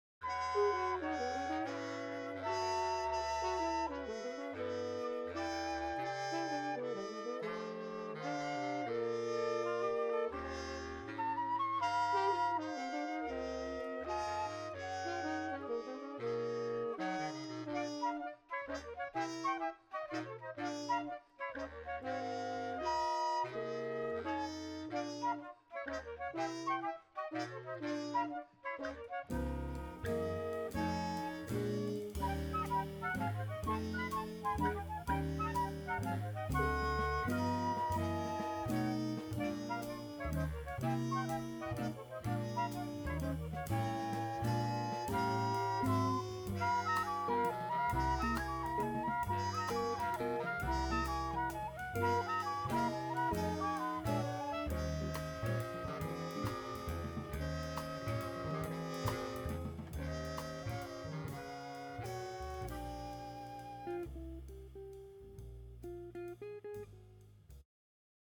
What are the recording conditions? Recorded Master Chord Studios January 2017